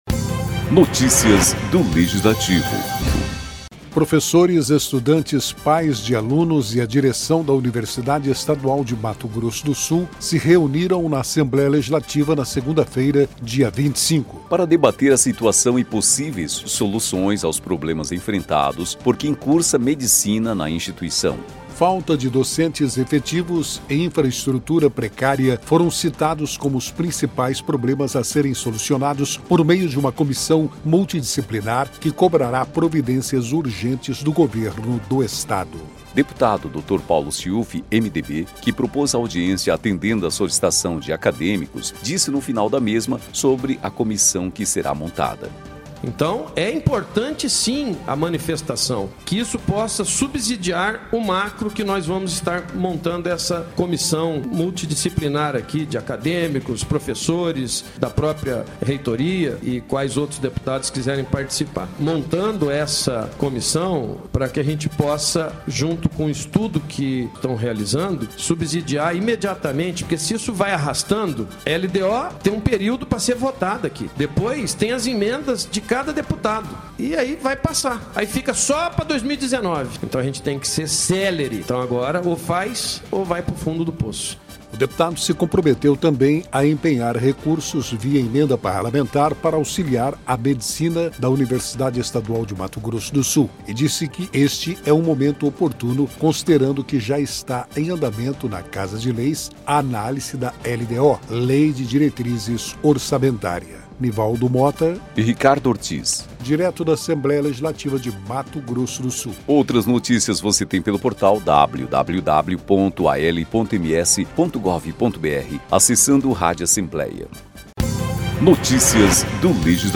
Audiência define comissão para cobrar melhorias para a Medicina da UEMS